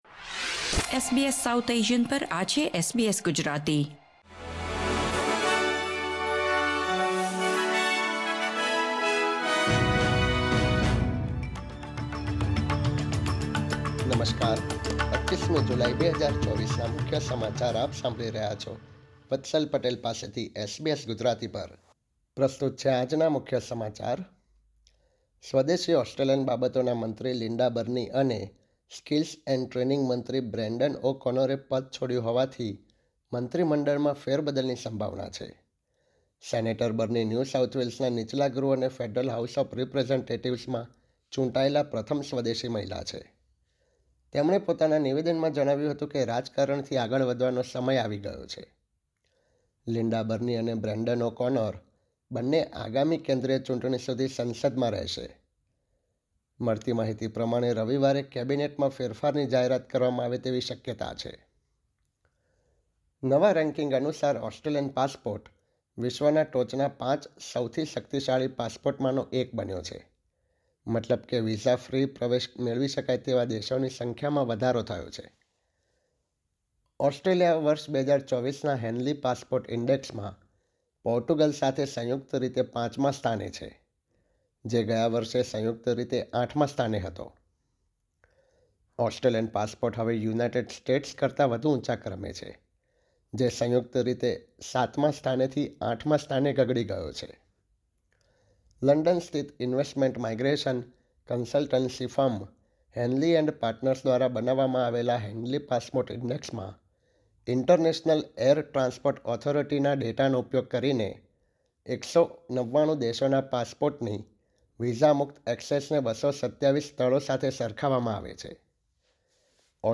SBS Gujarati News Bulletin 25 July 2024